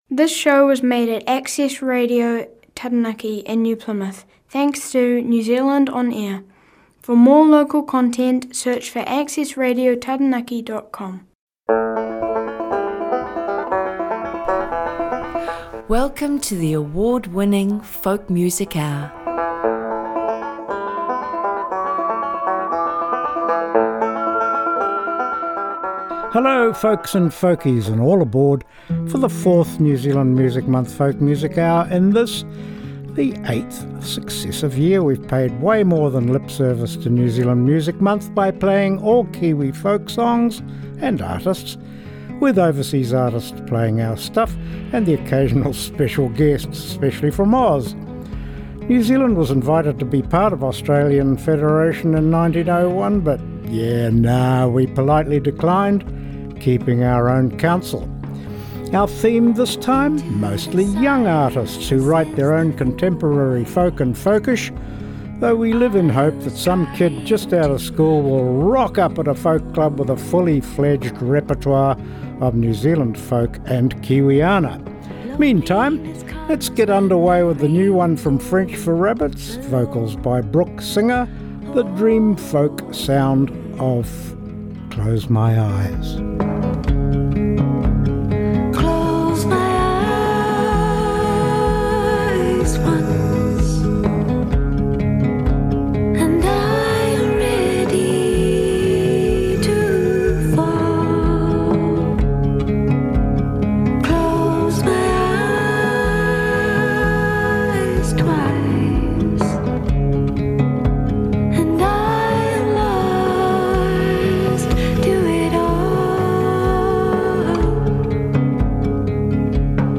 acoustic music in the Anglo-American tradition, including NZ folk song